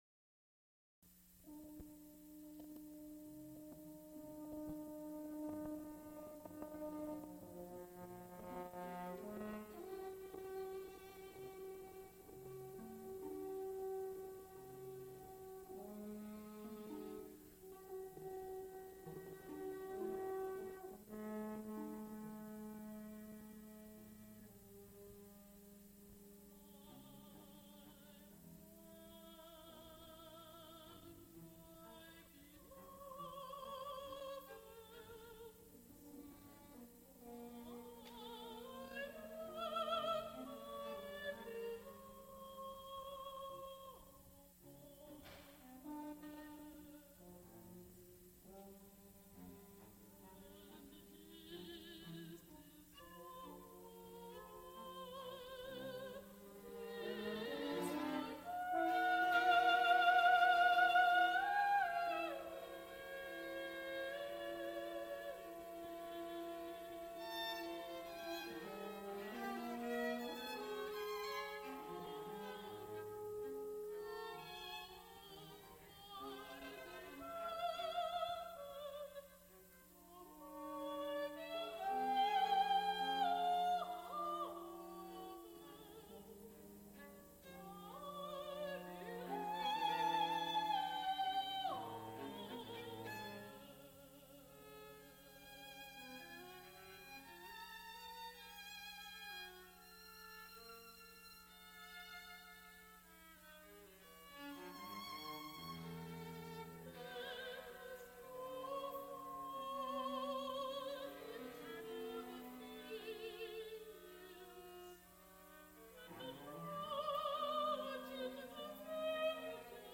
musical performances